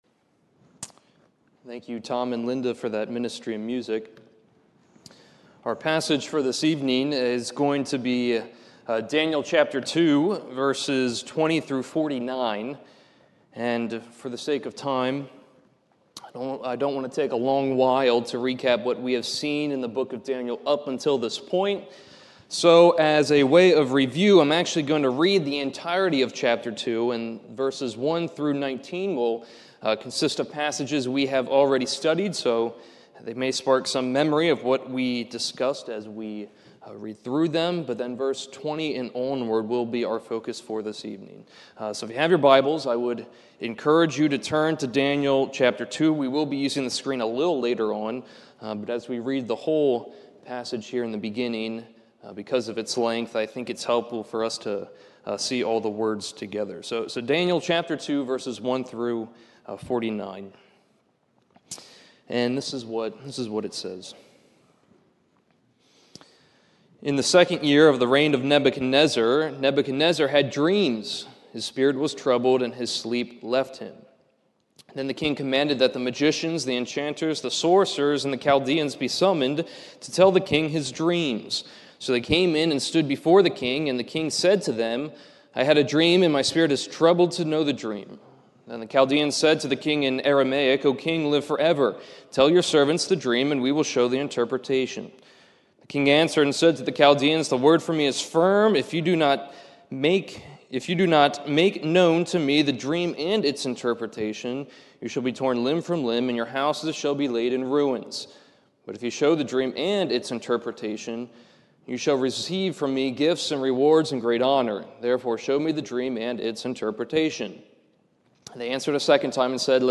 This is a sermon recorded at the Lebanon Bible Fellowship Church in Lebanon, PA during the evening worship service